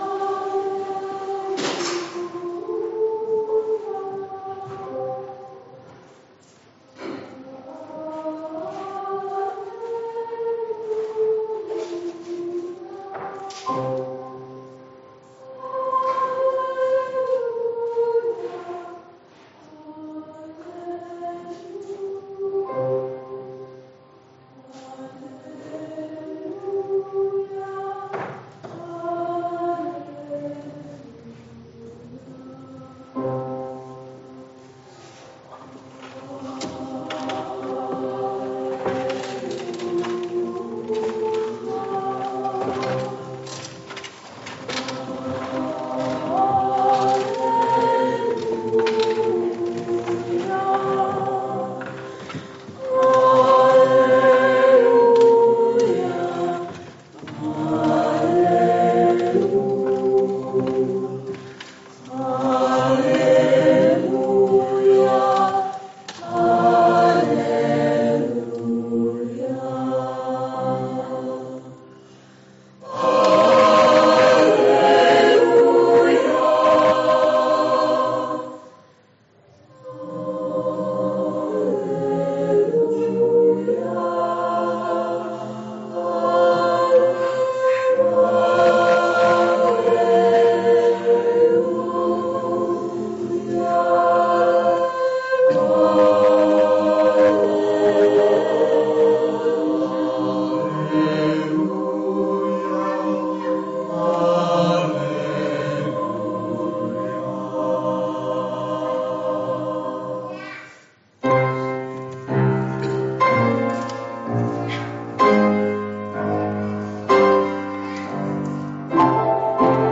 Gospel Meeting with Chorale – Newark Christian Fellowship
Messages shared by 5 brothers and sisters